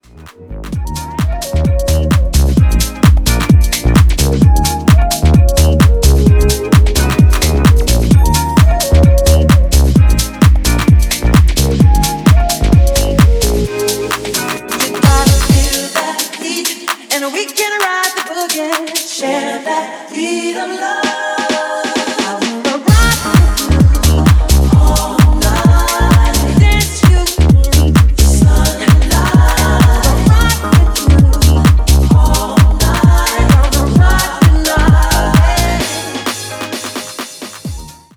Elektronisk Pop R'n'b House